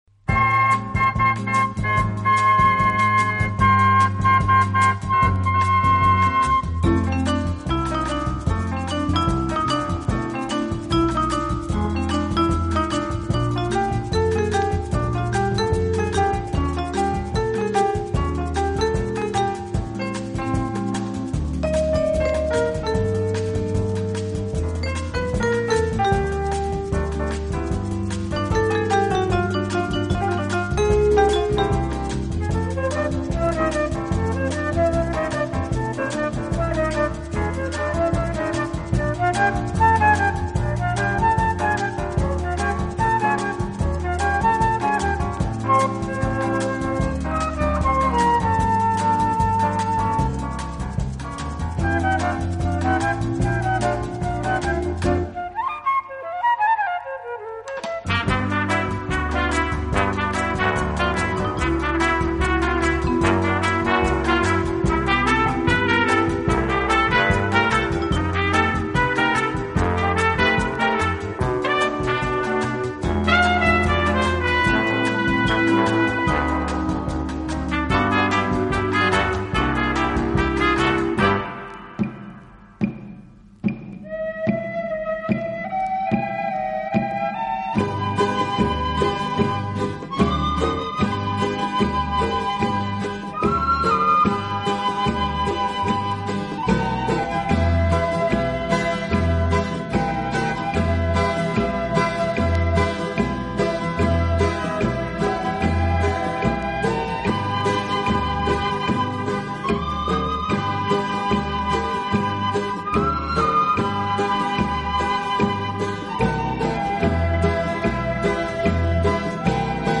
【轻音乐】
以擅长演奏拉丁美洲音乐而著称。